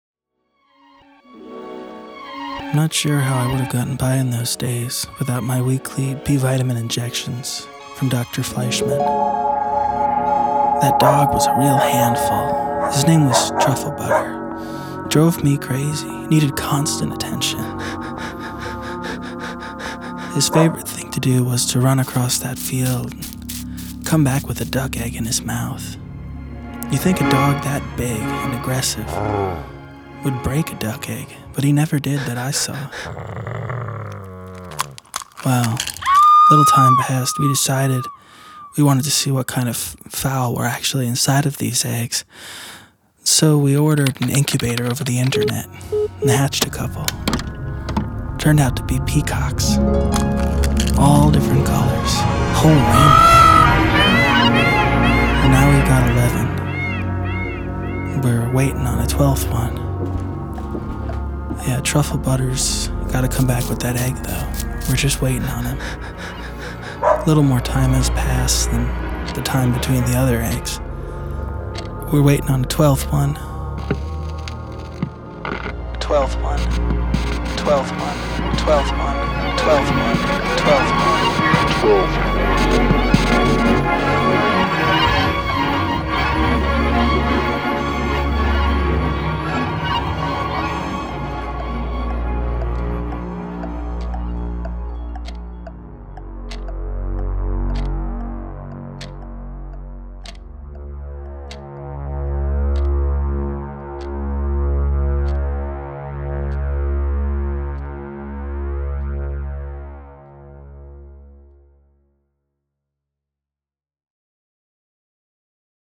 Sound / Experimental Radio / Bizarre. 2017.